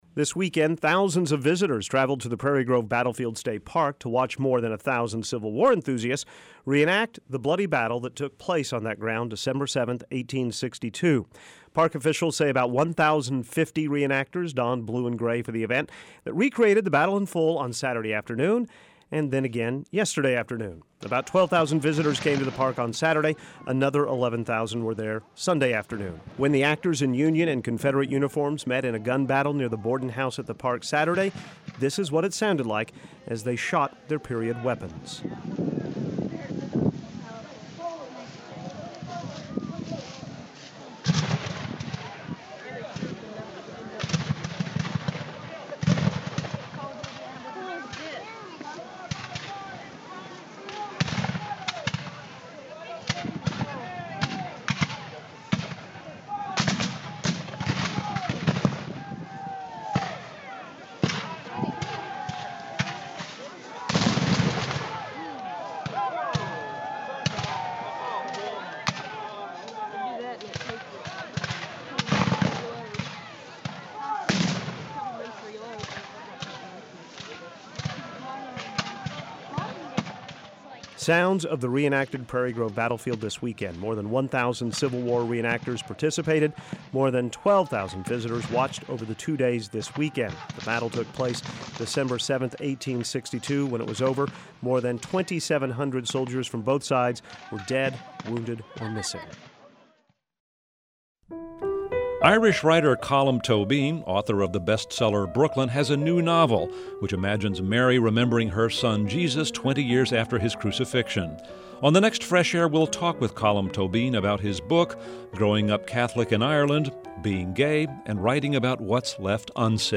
A reenactment of the Battle of Prairie Grove was held this weekend. This year's reenactment marks the 150th anniversary of the battle, and here is a montage of what that battle may have sounded like.